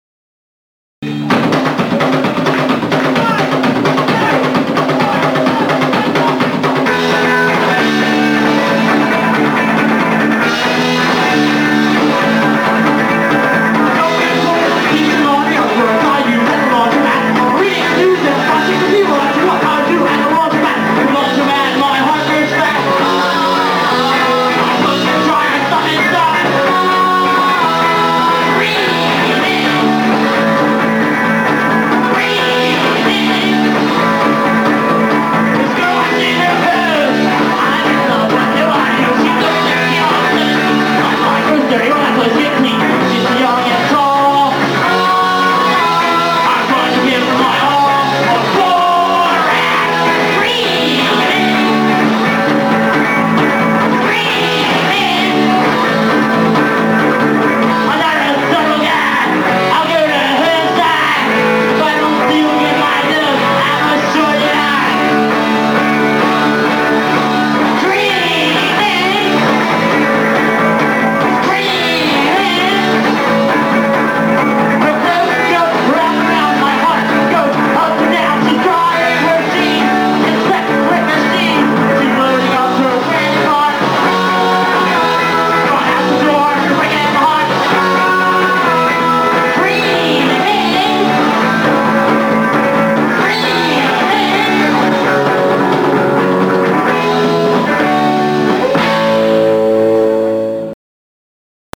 Elk’s Lodge Atlantic City 1985?